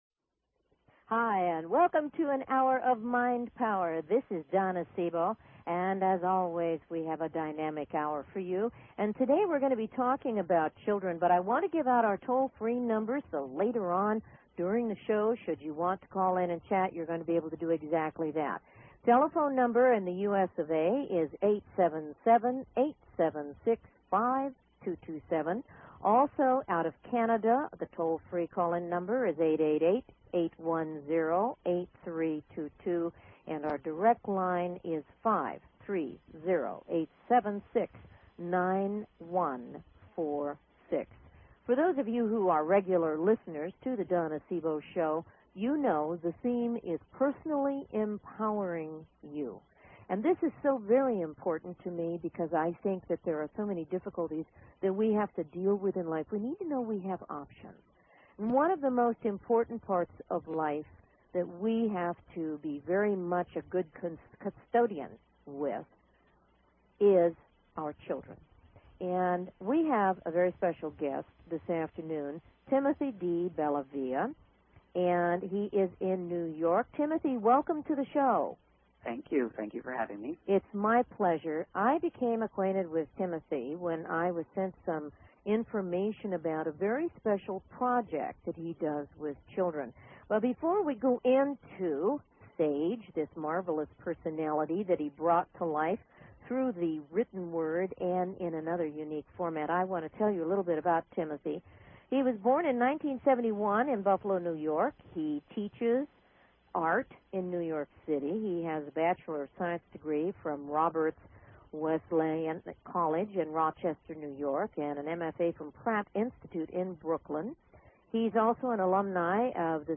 Talk Show Episode, Audio Podcast
Callers are welcome to call in for a live on air psychic reading during the second half hour of each show.